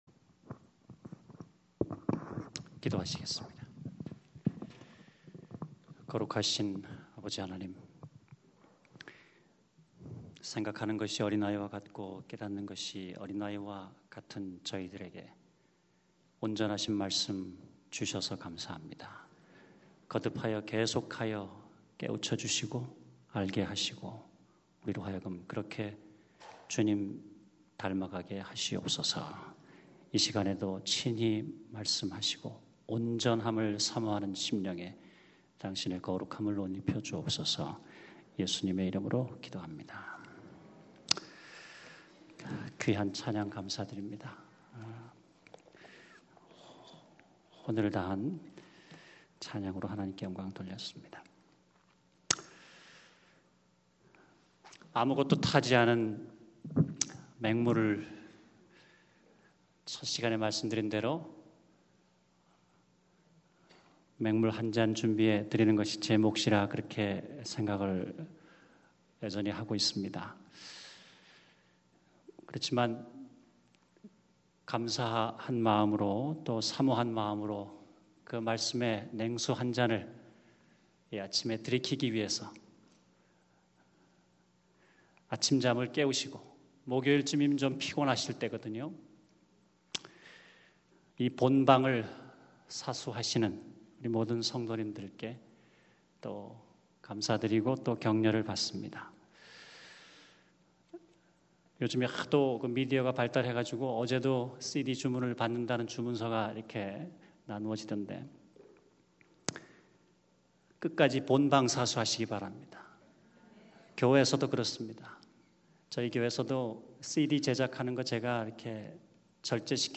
열매맺는 삶 (2011 서부야영회 새벽기도) > 방송 | 북미주한인협회 카스다